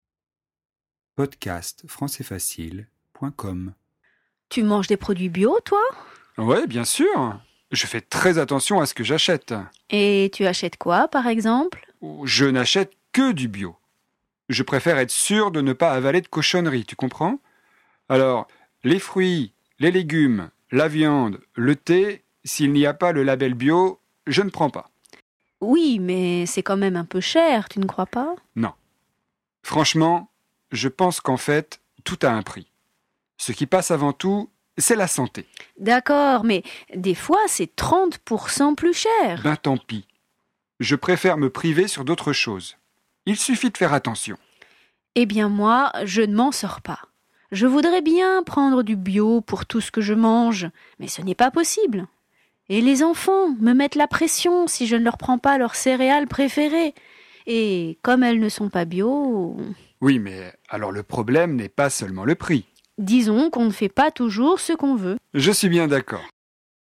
Dialogue en français, niveau intermédiaire, sur le thème de l'alimentation et l'écologie